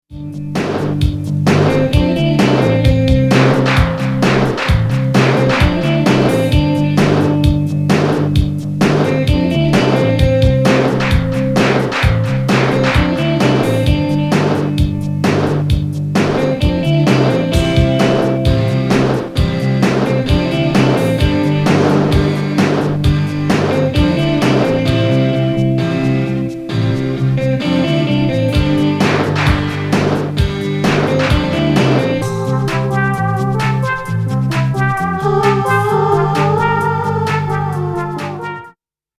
サンプリング・エレクトロ・ロッキン！！